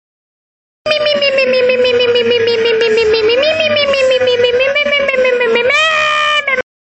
Mimimi Clash Royale Skeleton Sfx Soundboard: Play Instant Sound Effect Button